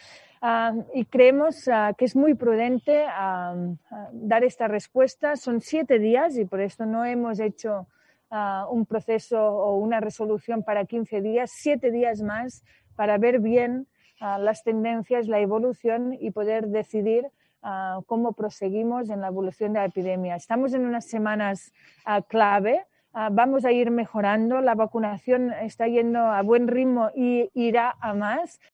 La Consellera de Salud Alba Vergés explica los motivos para prorrogar una semana más las medidas